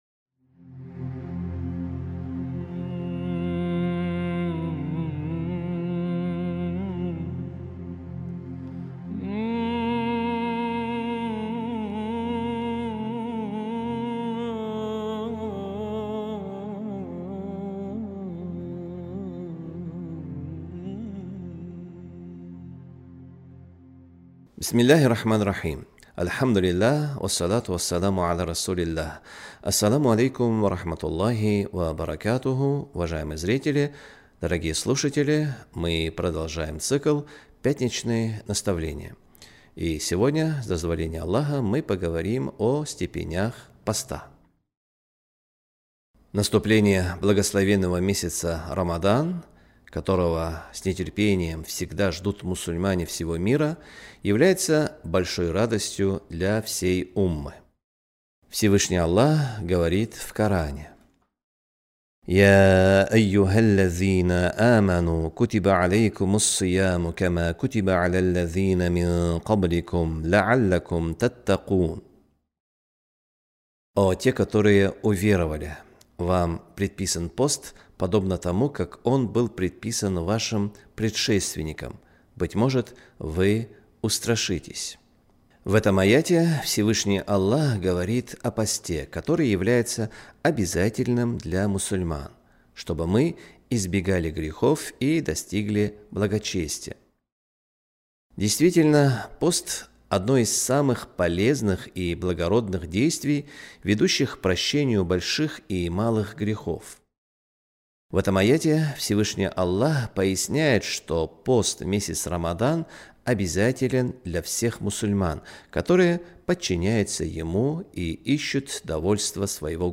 В лекциях этого цикла мы будем пояснять проповедь, которая проходит в эту пятницу в мечетях города.